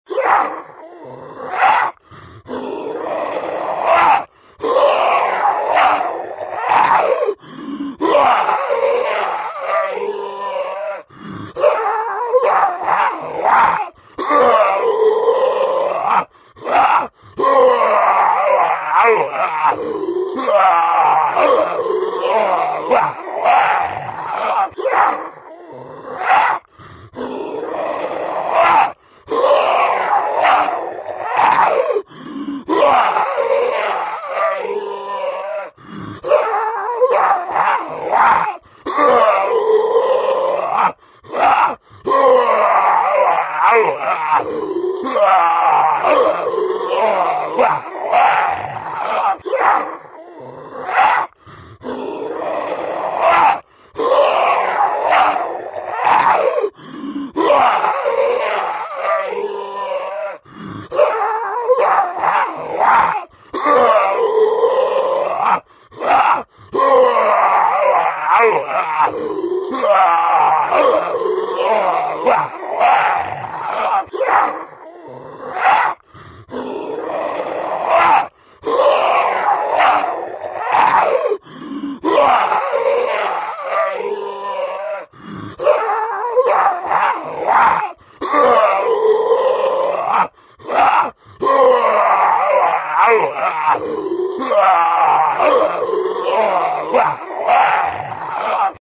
جلوه های صوتی
دانلود صدای زامبی از ساعد نیوز با لینک مستقیم و کیفیت بالا
برچسب: دانلود آهنگ های افکت صوتی انسان و موجودات زنده